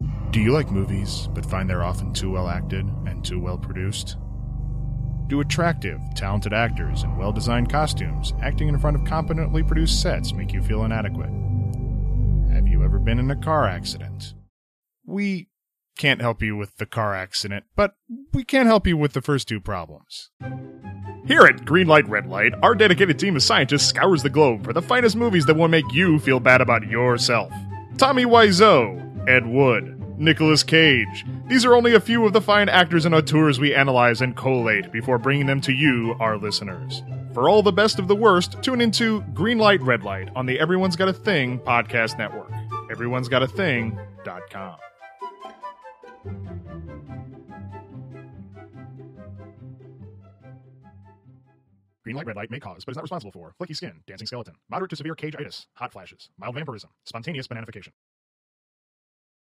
Music: